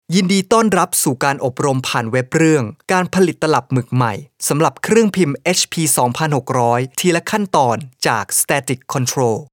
i have a home recording studio with considerable audio equipments such as Nueman TLM 103 microphone, Focusrite Class A preamp, KRK V6 studio monitor and Digidesign Mbox.
I am a professional Thai voice talent.
Sprechprobe: Werbung (Muttersprache):